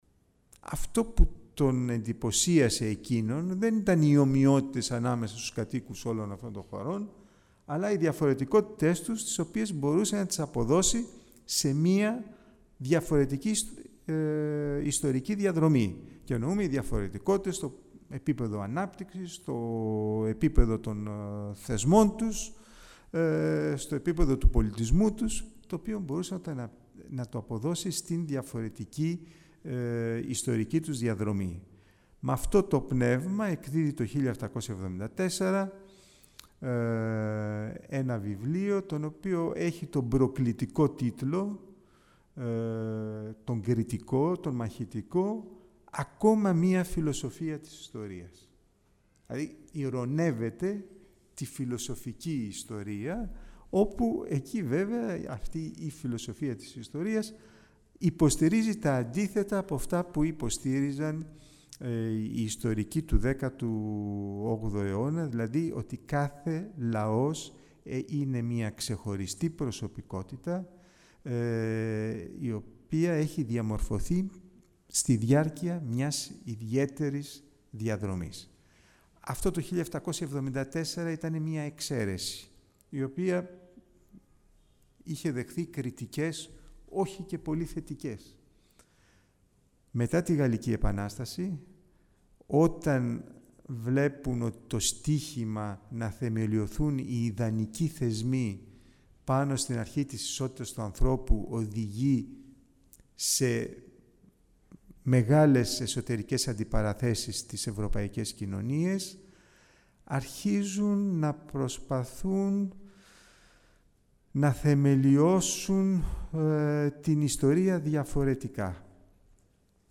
16 June 2004 at the chamber Theotokopoulos two lectures with the subject:"The origins of the Science of History" .